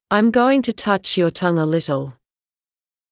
ｱｲﾑ ｺﾞｰｲﾝｸﾞ ﾄｩ ﾀｯﾁ ﾕｱ ﾀﾝｸﾞ ｱ ﾘﾄﾙ